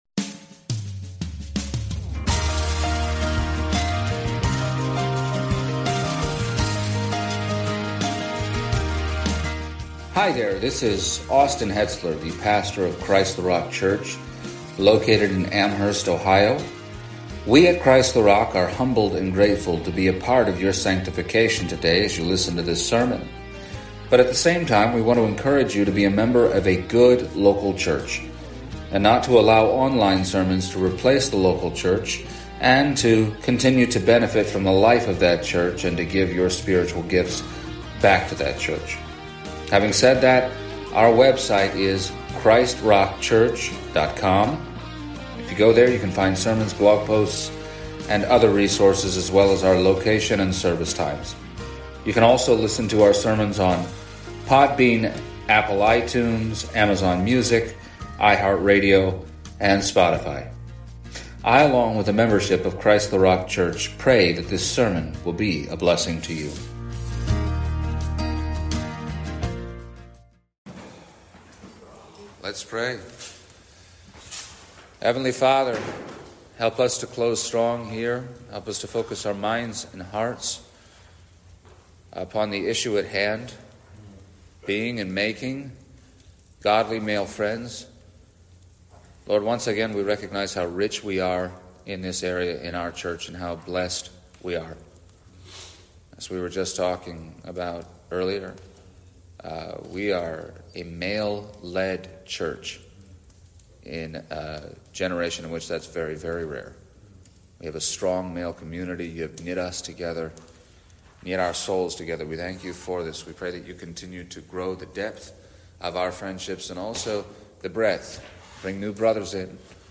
The third of three messages given at the inaugural CtRC Men’s Summit